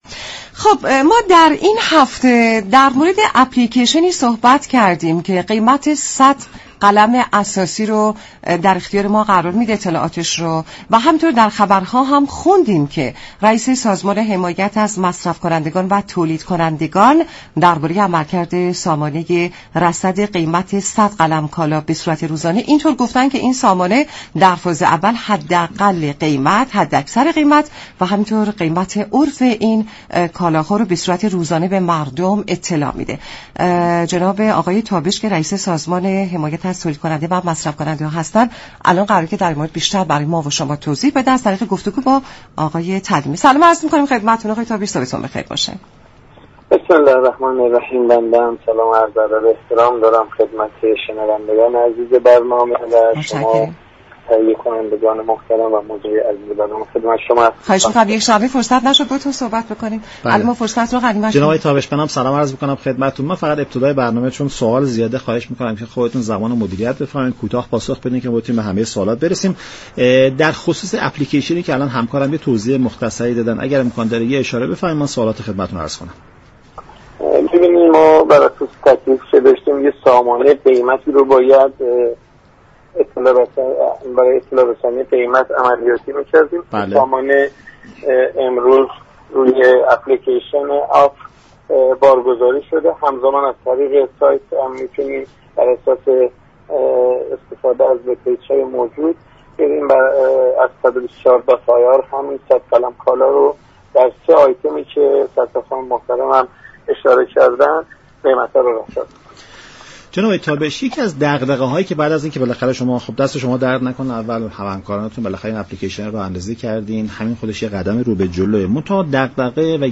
به گزارش شبكه رادیویی ایران، عباس تابش معاون وزیر صنعت ، معدن و تجارت و رییس سازمان حمایت از تولید كننده و مصرف كننده در گفت و گو با برنامه نمودار درباره راه اندازی نرم افزار قیمت صد قلم كالای اساسی گفت: نرم افزار قیمت صد قلم كالای اساسی هم اكنون در سیستم آپ بارگذاری شده است.